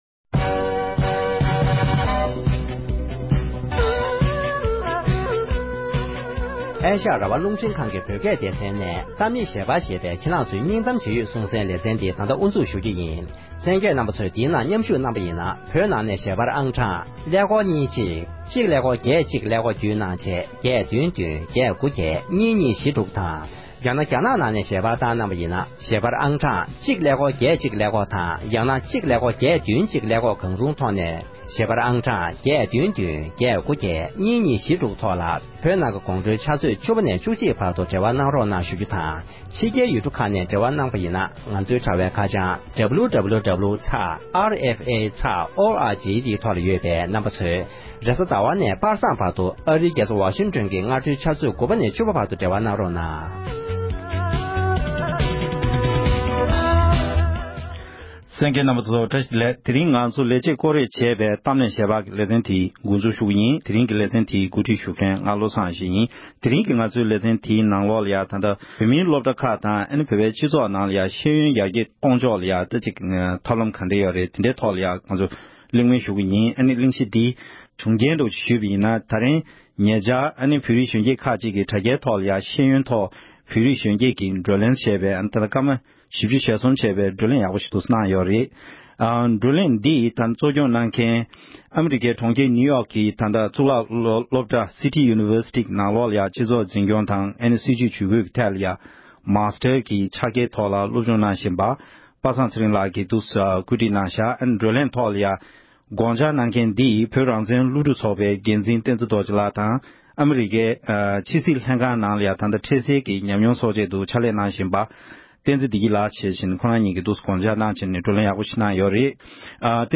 བོད་མིའི་སློབ་གྲྭ་དང་སྤྱི་ཚོགས་ནང་ཤེས་ཡོན་ཡར་རྒྱས་གཏོང་ཕྱོགས་ཐད་འབྲེལ་ཡོད་མི་སྣ་ཁག་དང་བགྲོ་གླེང་ཞུས་པ།